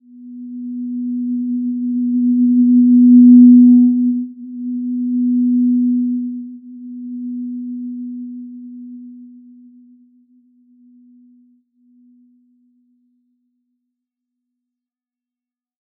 Simple-Glow-B3-mf.wav